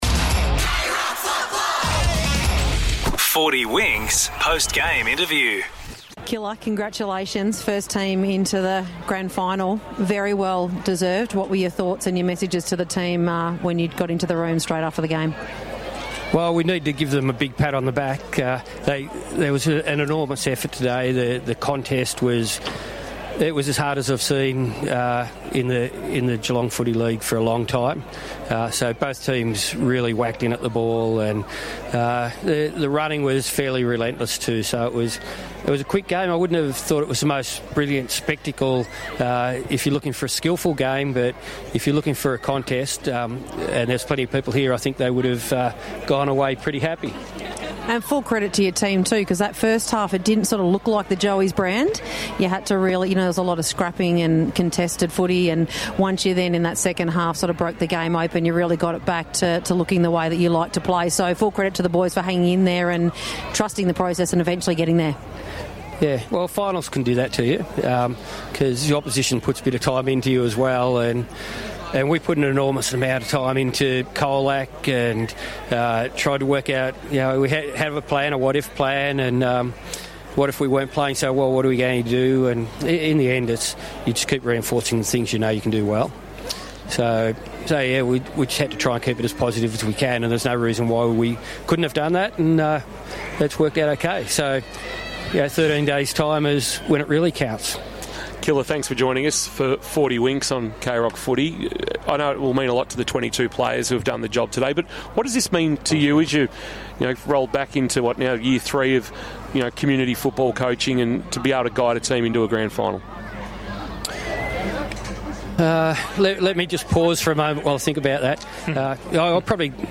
2025 - GFNL - Second Semi-Final - St Joseph's vs. Colac - Post-match interview